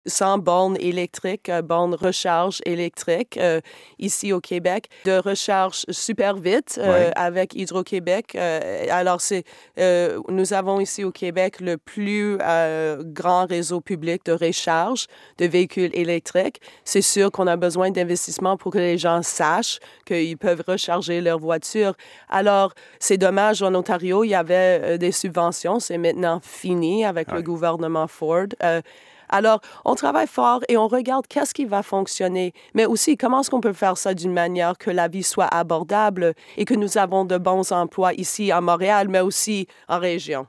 Algo incomprensible para muchas personas y Catherine McKenna, ministra del Medioambiente de Canadá decía esta mañana en entrevista con la radio francesa de Radio Canadá, que efectivamente, ese es un comentario que ella escucha de parte de los canadienses, pero…